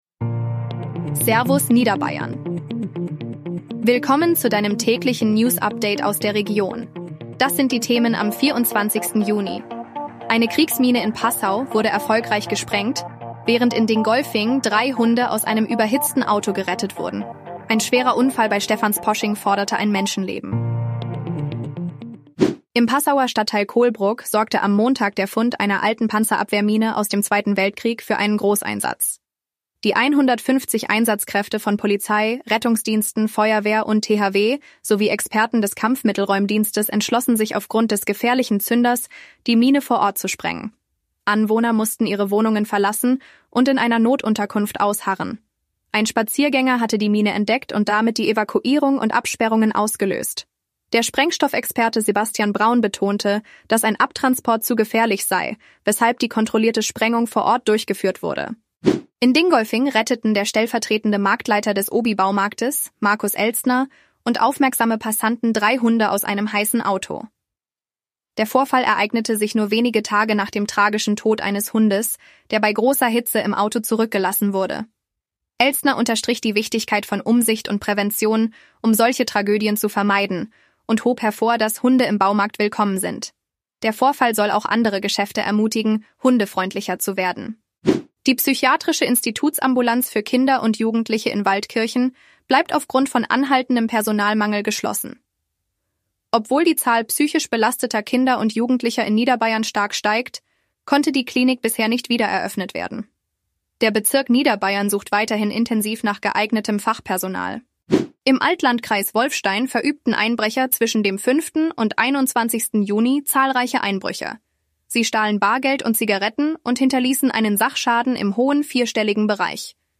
Dein tägliches News-Update